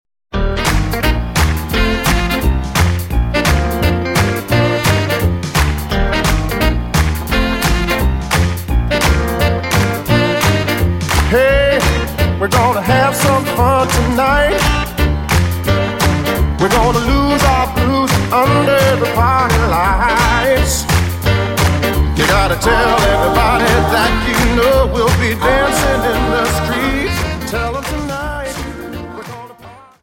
Dance: Jive Song